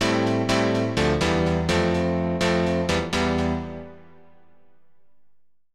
PIANO016_VOCAL_125_A_SC3(L).wav
1 channel